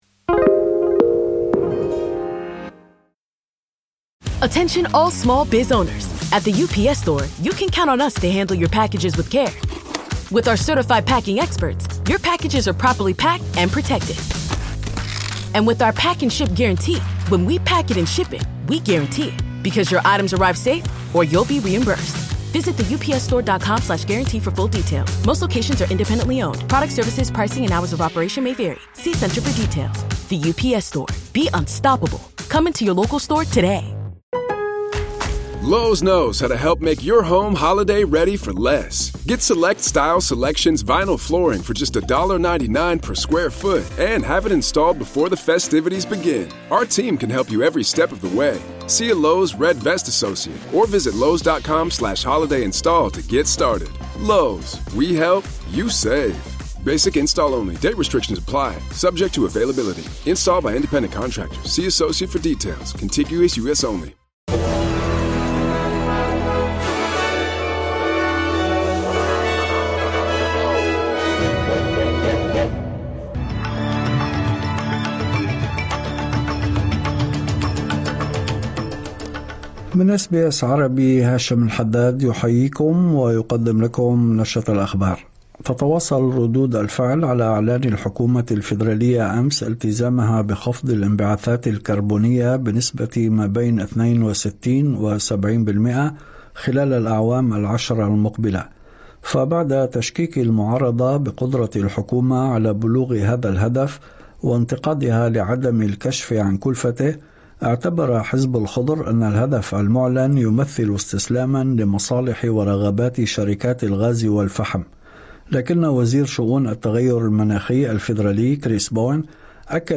نشرة أخبار الظهيرة 19/09/2025